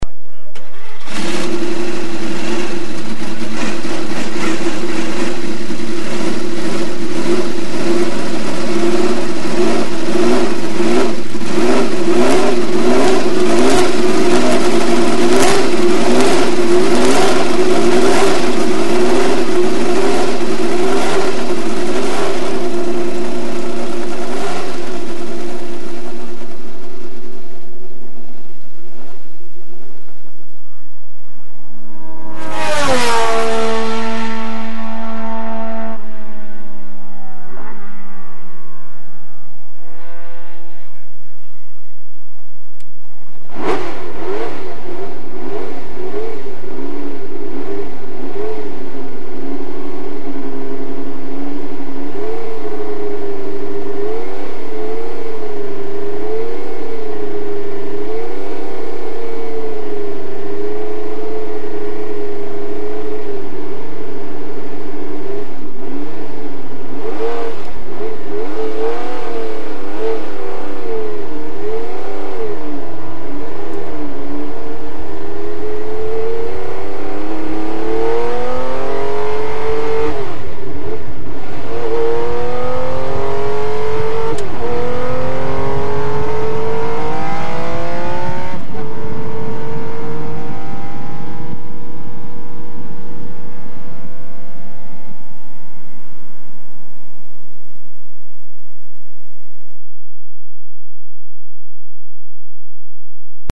A jármű hangja általában hármas felosztásban hallható:
indulás, elhaladás, kocsiban ülve.
Ferrari_312t3.mp3